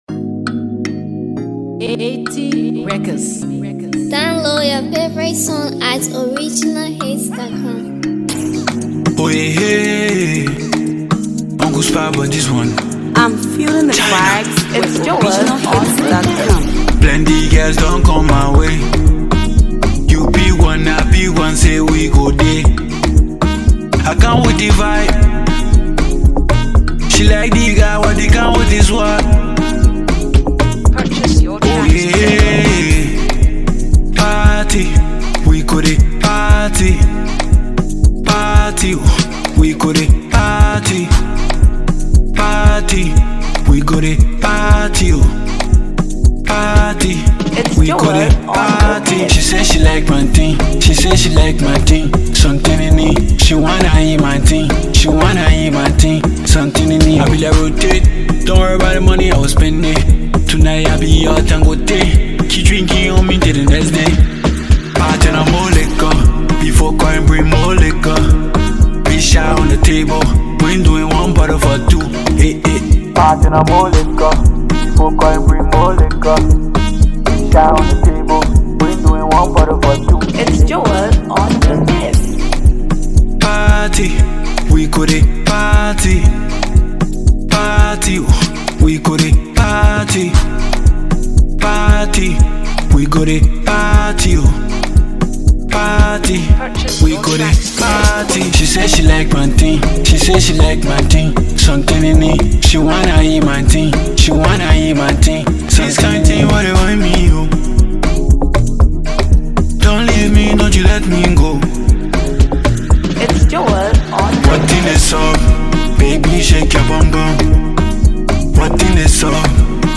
the young versatile vocalist artist